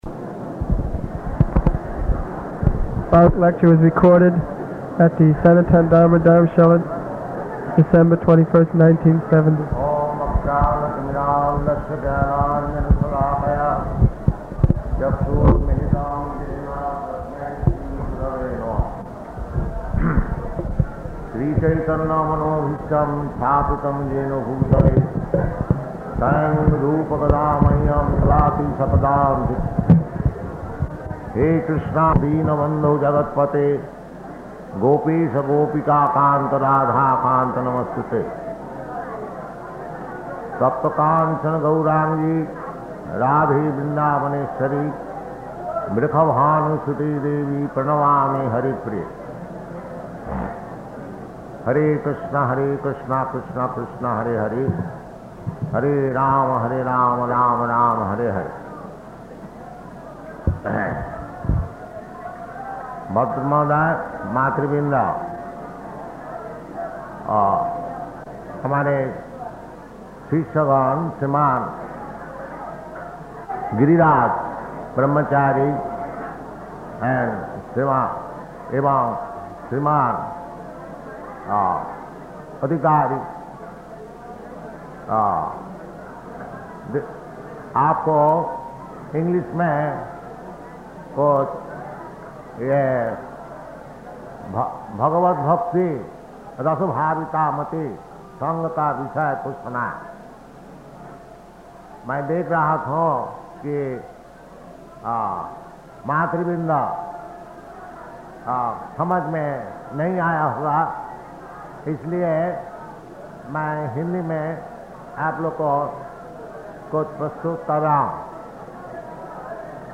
Lecture in Hindi
Type: Lectures and Addresses
Location: Surat